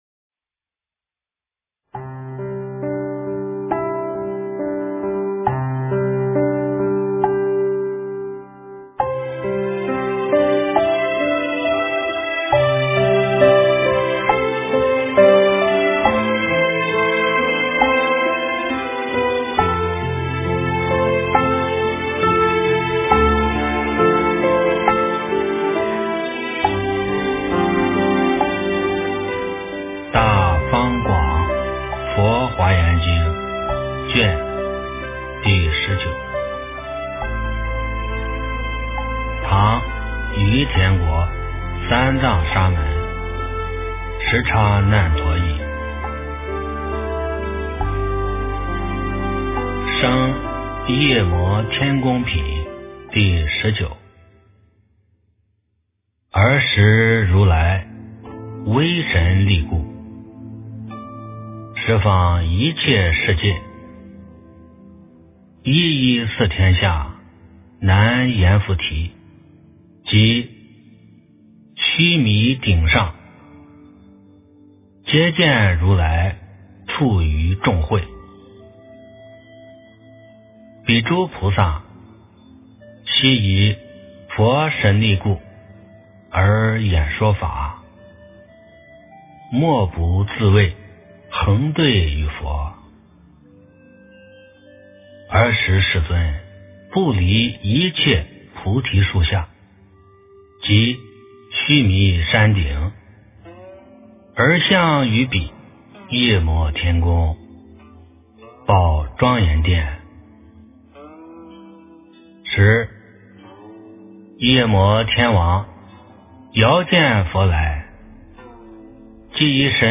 诵经
佛音 诵经 佛教音乐 返回列表 上一篇： 金光明经-舍身品第十七 下一篇： 《华严经》17卷 相关文章 楞严神咒二部曲-释尊应化会--居士团 楞严神咒二部曲-释尊应化会--居士团...